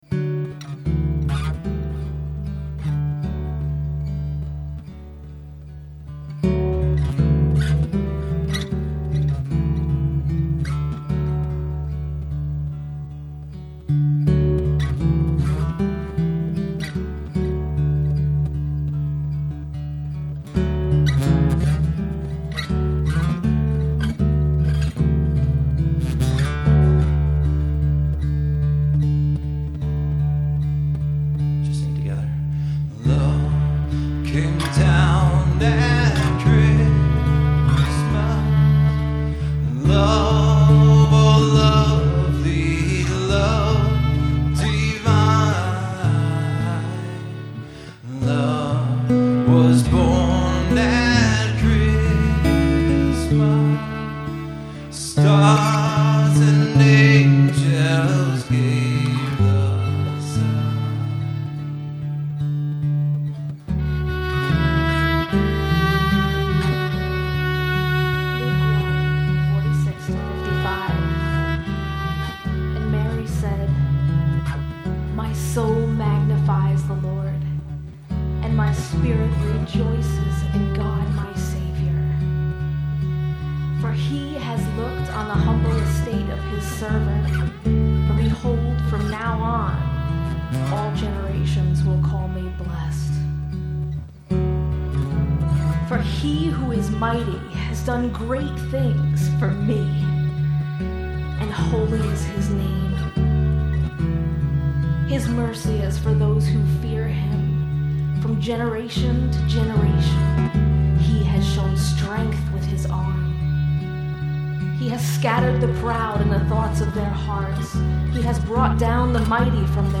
Performed live at Terra Nova - Troy on 12/20/09.